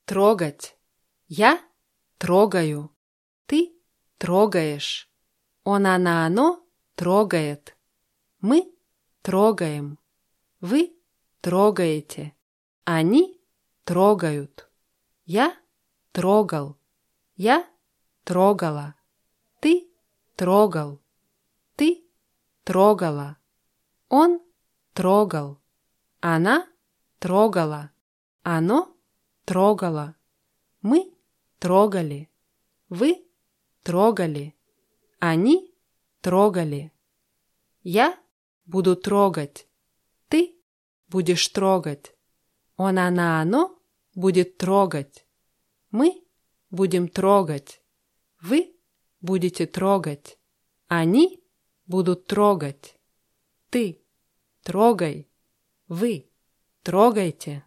трогать [trógatʲ]